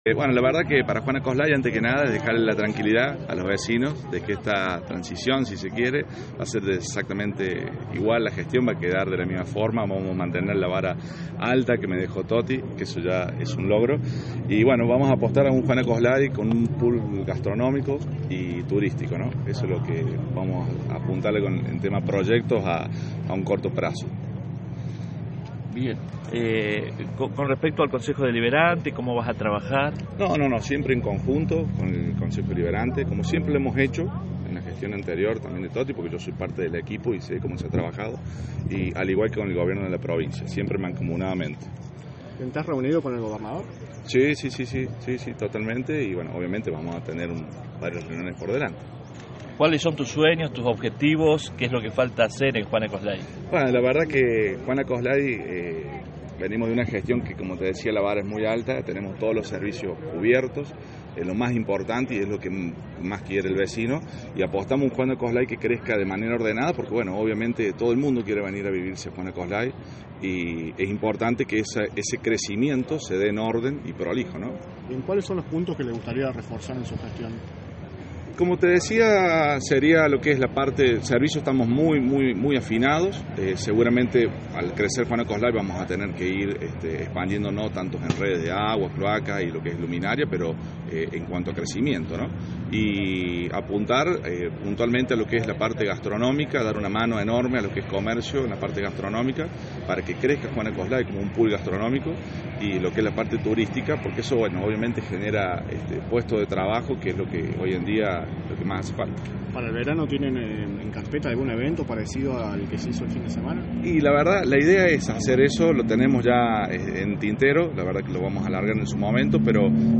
Tagliente habló con los periodistas y medios de prensa presentes en el acto y esto dijo: